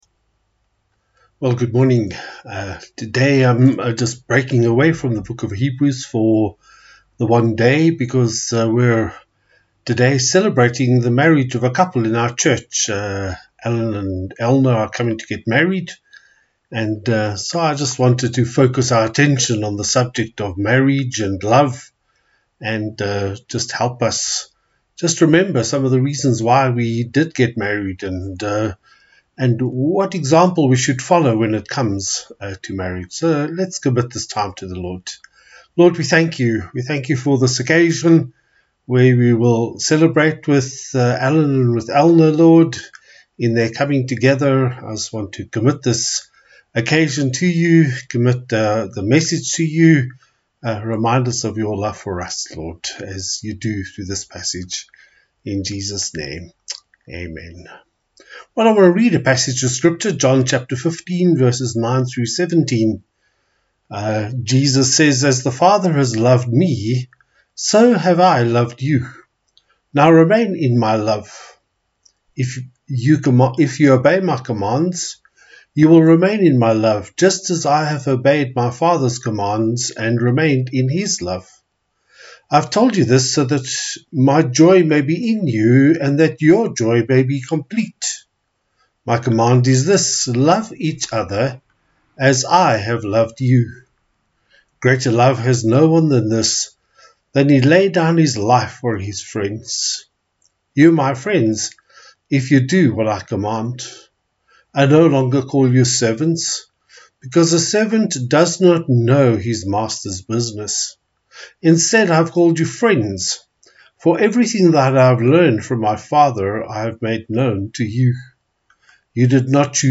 THE LOVE OF JESUS – Wedding Sermon